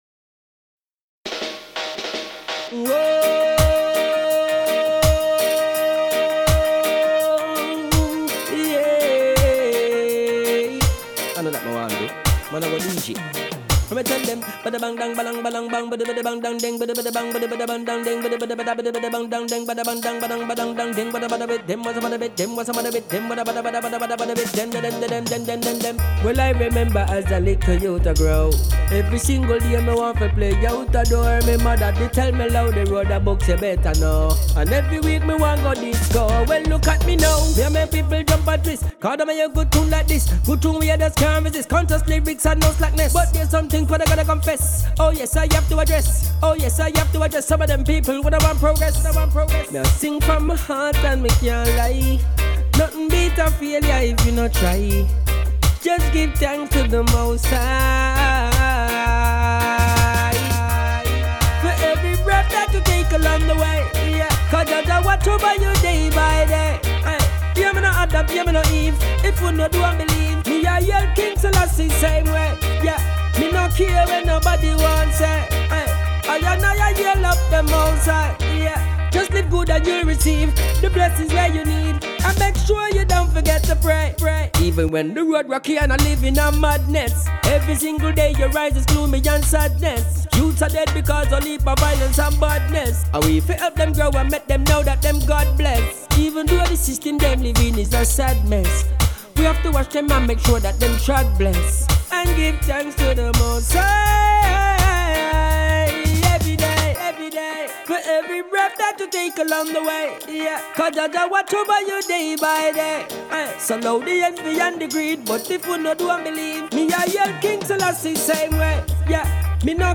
Genre: Telugu